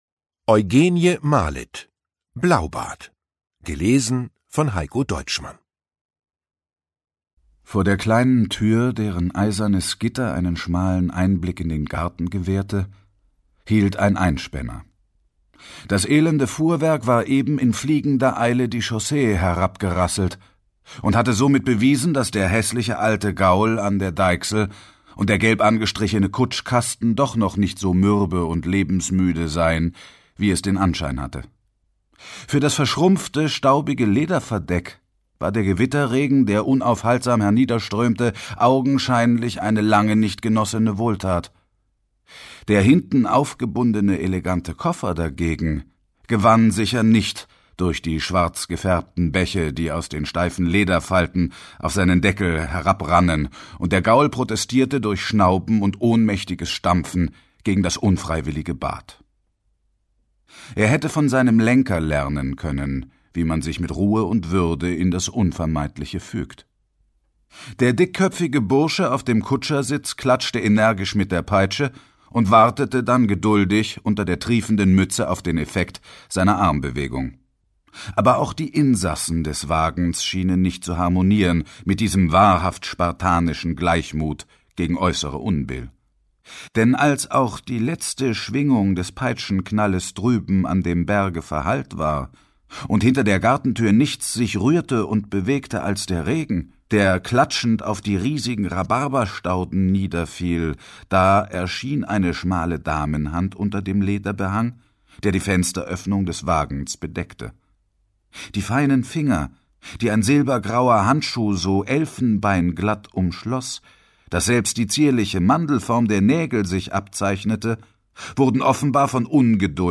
Lesung mit Heikko Deutschmann (1 mp3-CD)
Heikko Deutschmann (Sprecher)